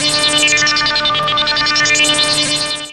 Synth-DL.wav